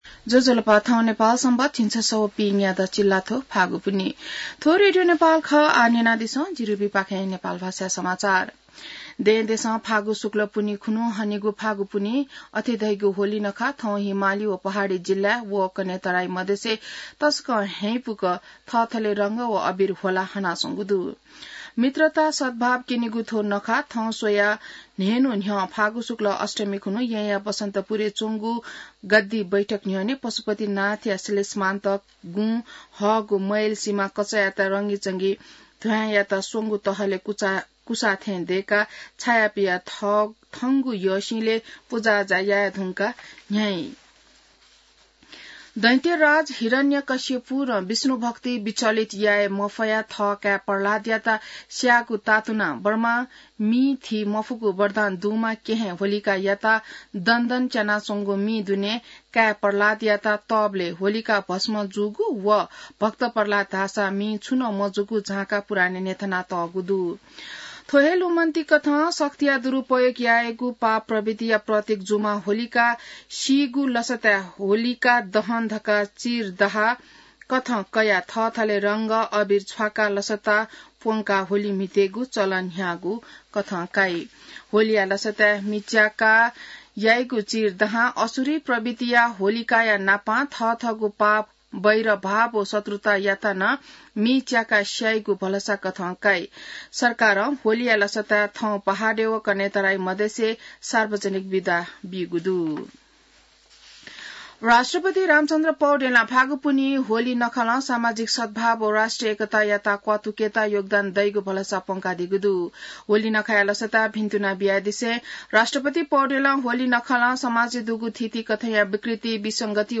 नेपाल भाषामा समाचार : ३० फागुन , २०८१